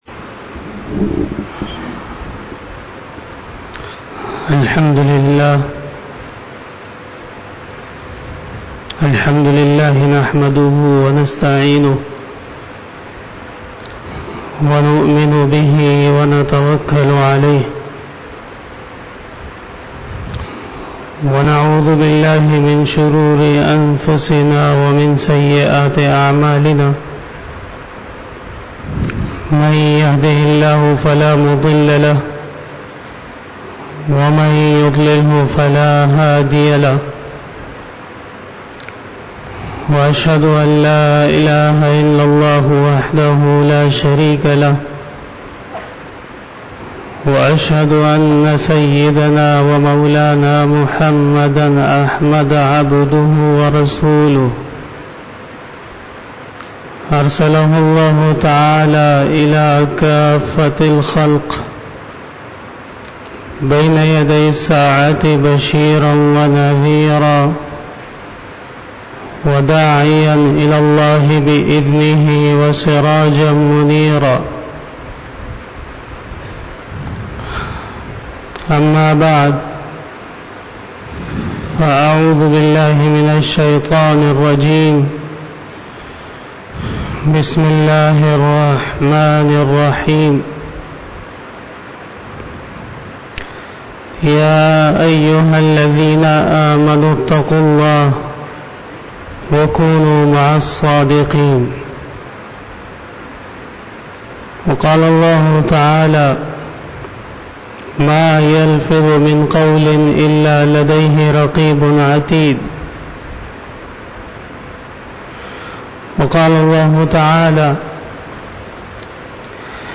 44 BAYAN E JUMA TUL MUBARAK (01 November 2019) (03 Rabi Ul Awwal 1441H)
Khitab-e-Jummah 2019